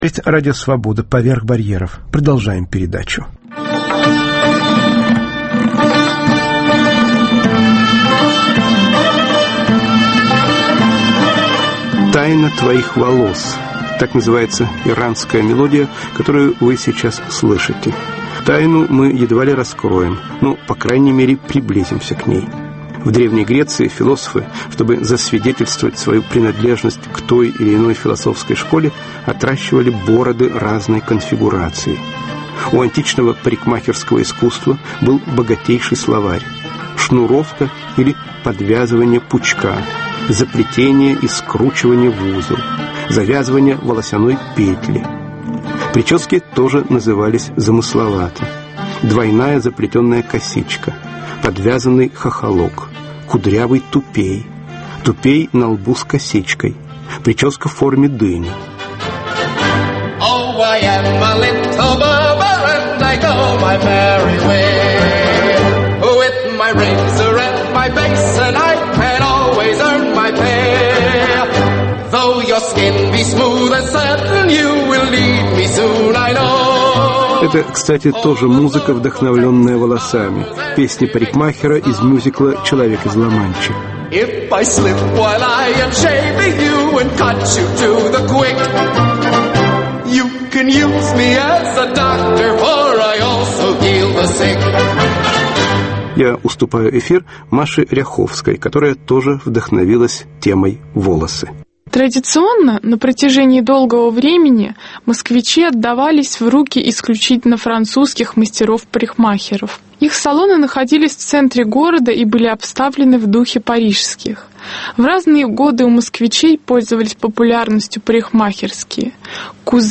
"Тайна волос": передача с участием парикмахеров, историка культуры, поэтессы и др